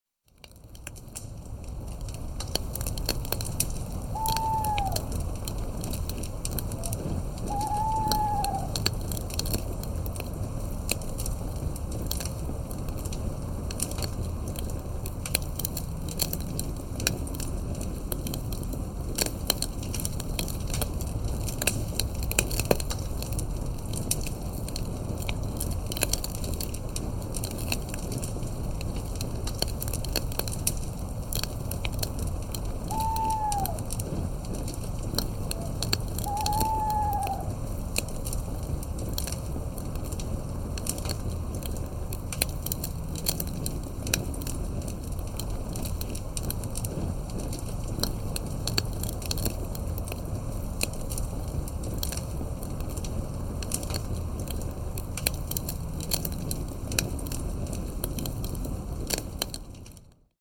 campfire1m.mp3